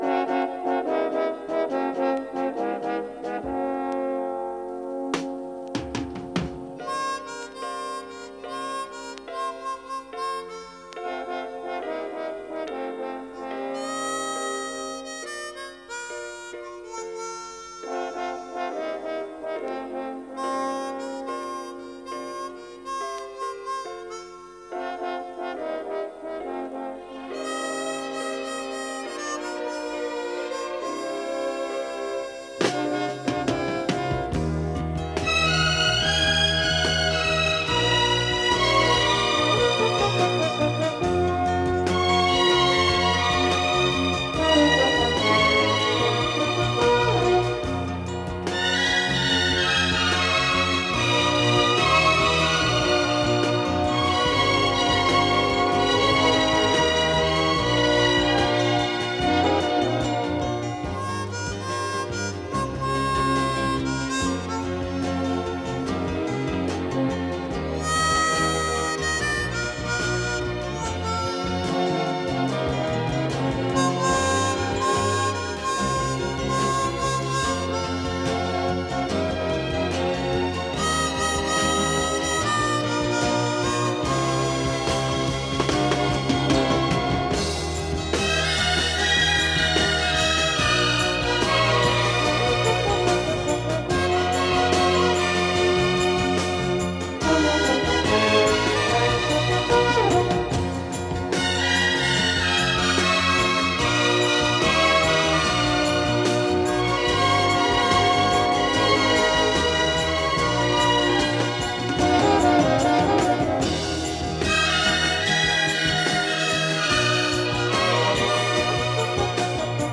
Скрипки всегда были главными «певцами» его оркестра.